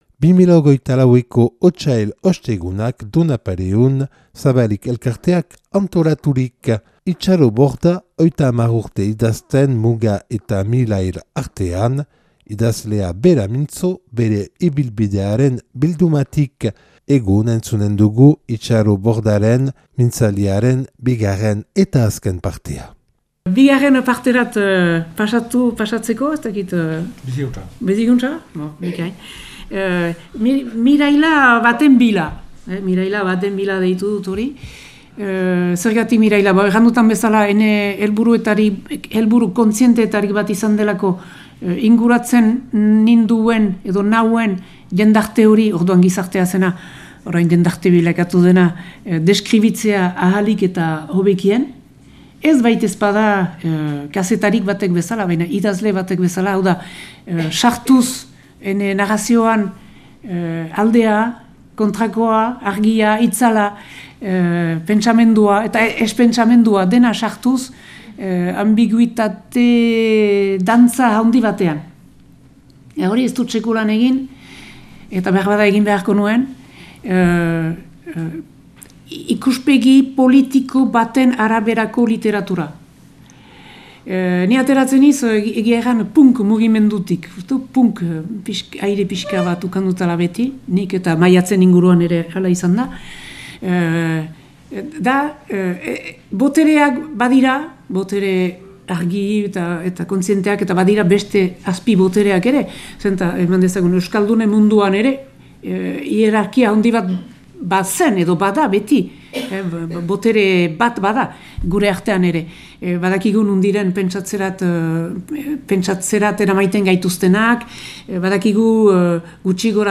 Idazlea bera mintzo, bere ibilbidearen bildumatik.Otsail Ostegunak 2024 Donapaleun Otsailaren 15ean Zabalik elkarteak antolaturik.